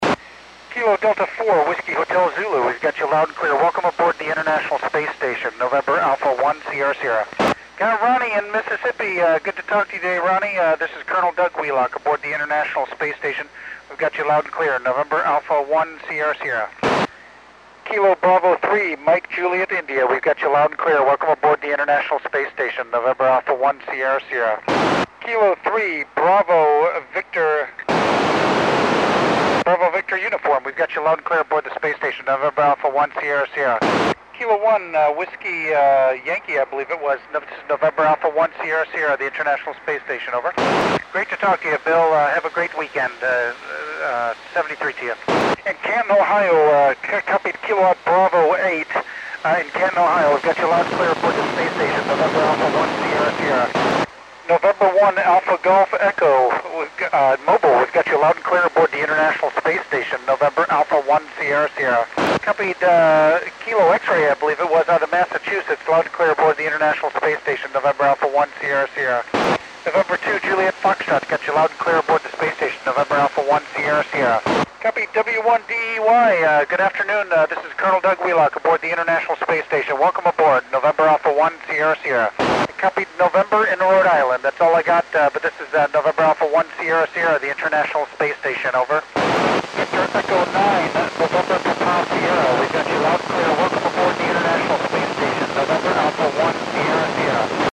Col. Doug Wheelock works U.S. and Canadian stations on 11 September 2010 at 1719 UTC.